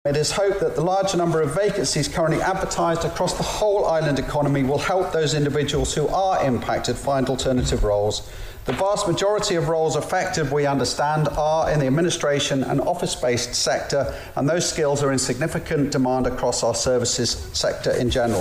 Mr Cannan told Tynwald he was hopeful they'd be able to find new posts quickly: